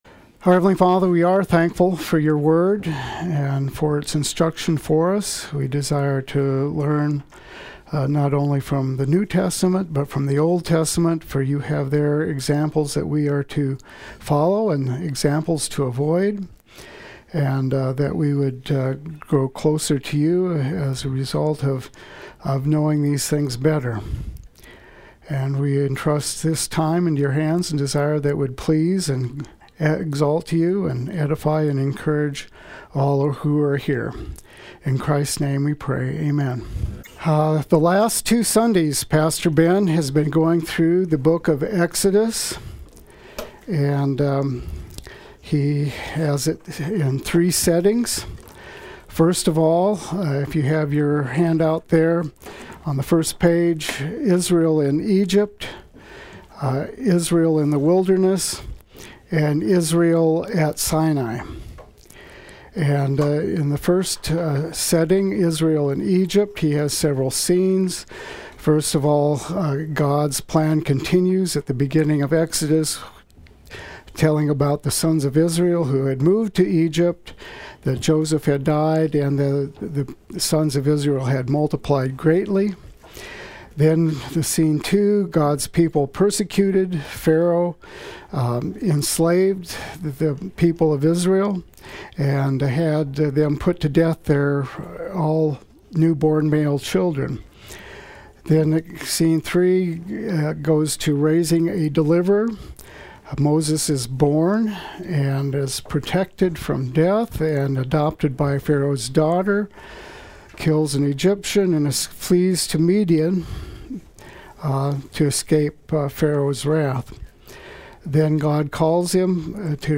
Play Sermon Get HCF Teaching Automatically.
Exodus 25-40 Adult Sunday School